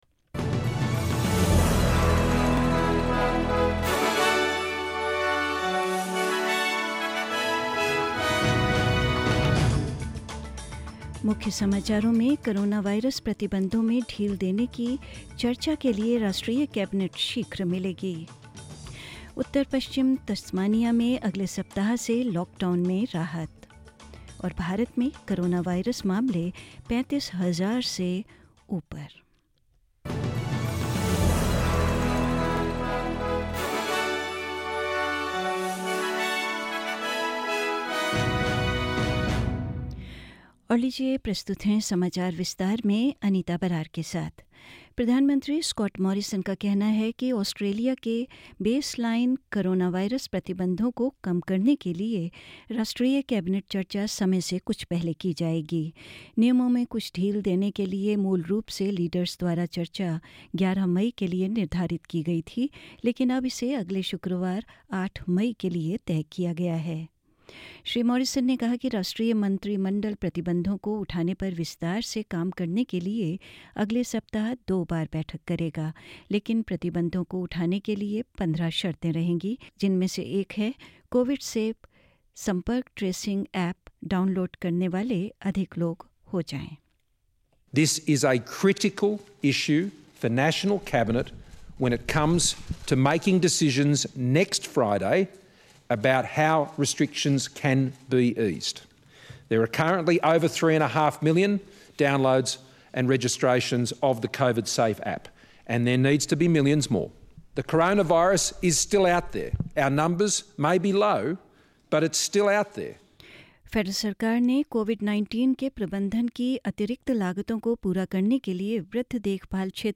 Hindi News 1/5/20